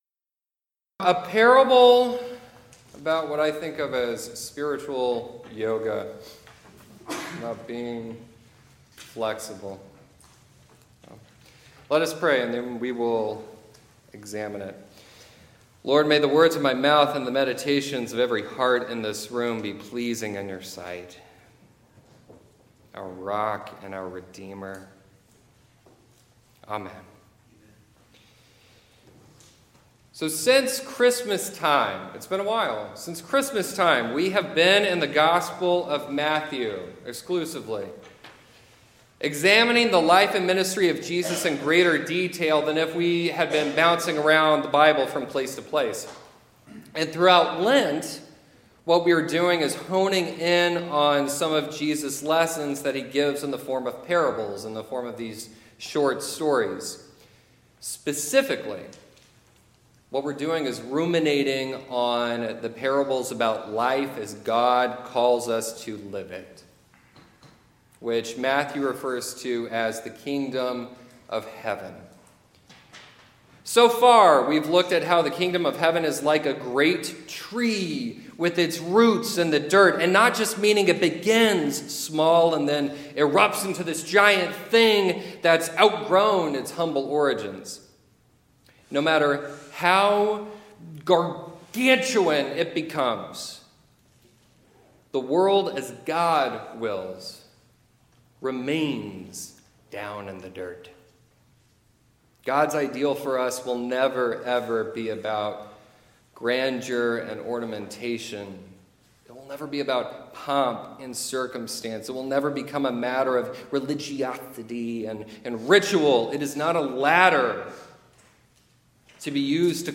(Editor’s Note. The sermon ends in an unusual way, which was acknowledged and corrected later in the service)